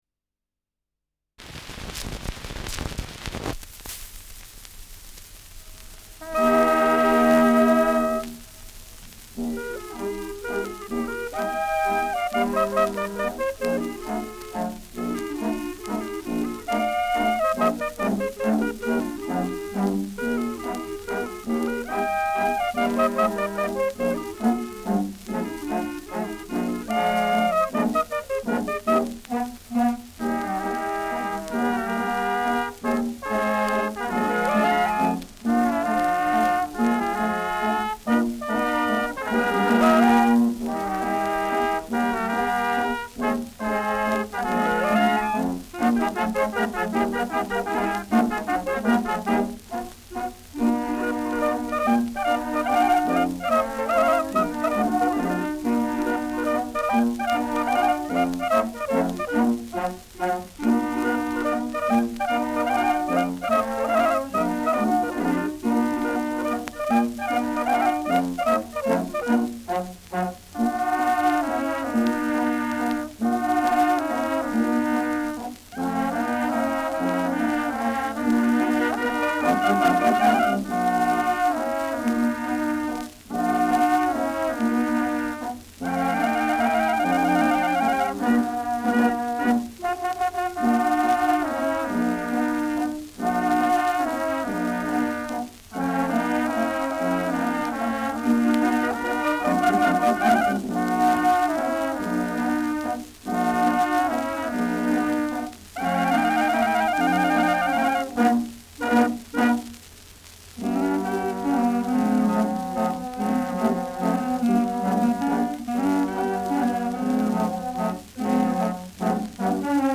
Schellackplatte
Tonrille: Kerbe 6 Uhr Stark : Kratzer 9 / 12 Uhr Leicht
Wiener Bürgerkapelle (Interpretation)